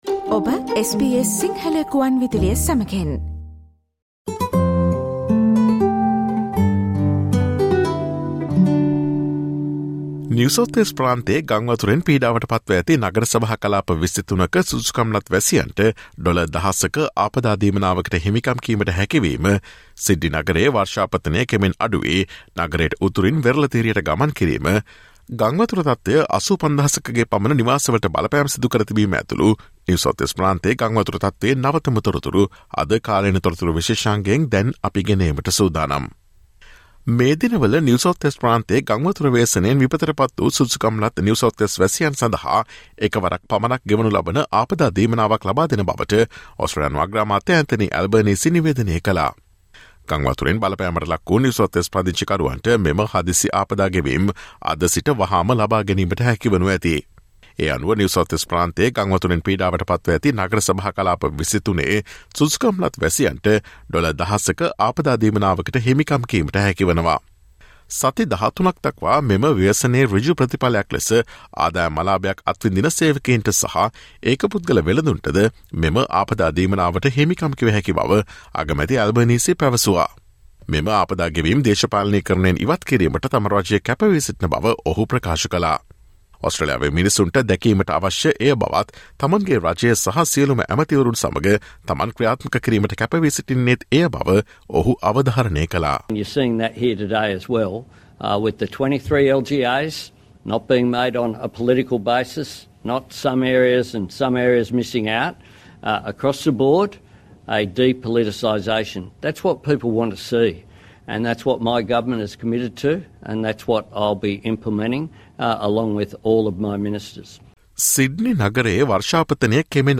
Listen to SBS Sinhala's current affairs feature, which aired on Thursday, 07 July, featuring the latest situation of NSW flood.